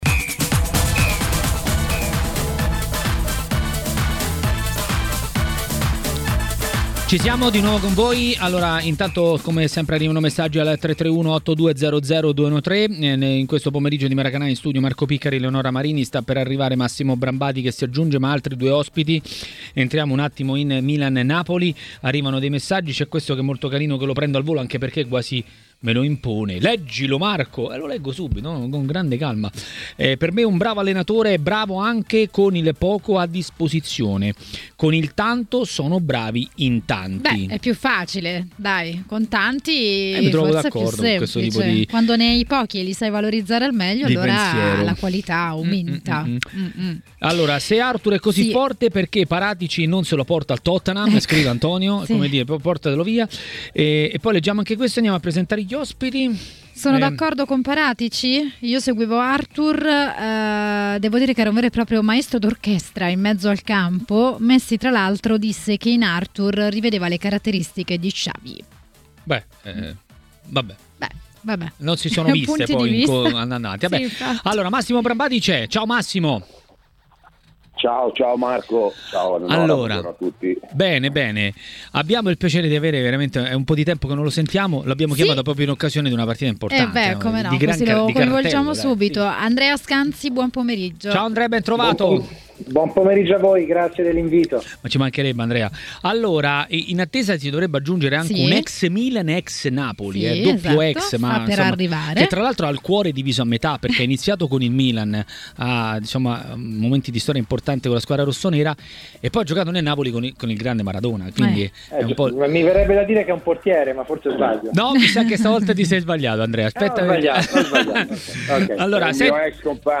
A dire la sua su Milan-Napoli a Maracanà, nel pomeriggio di TMW Radio, è stato l'ex calciatore e tecnico Giuseppe Incocciati.